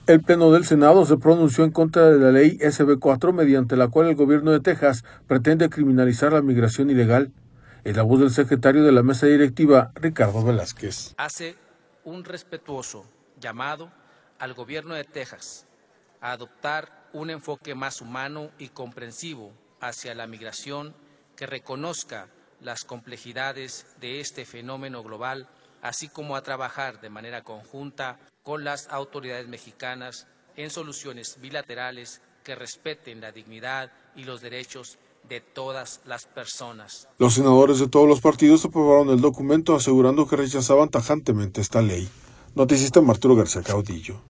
El Pleno del Senado se pronunció en contra de la Ley SB4 mediante la cual el gobierno de Texas pretende criminalizar la migración ilegal. Es la voz del secretario de la Mesa Directiva, Ricardo Velázquez.